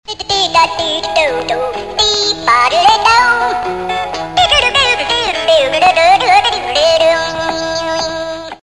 Unos simpáticos ratones cantan una canción divertida.